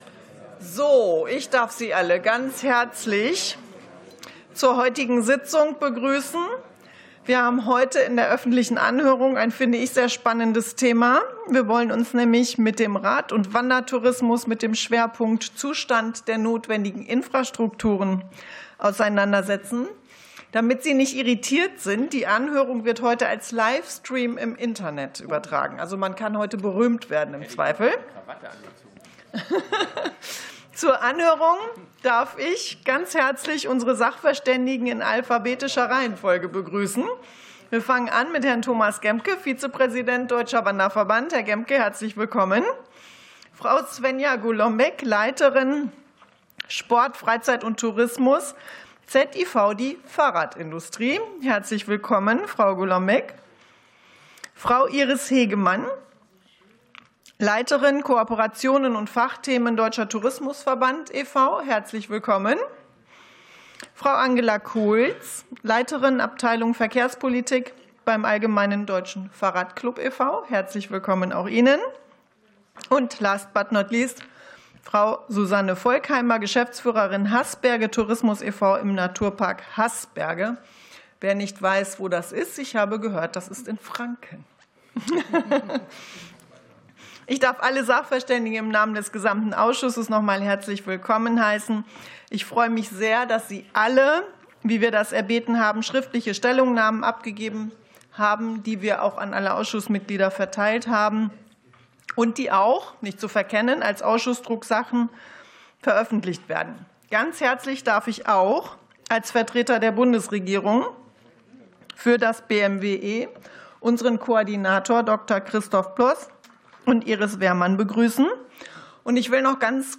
Anhörung des Ausschusses für Tourismus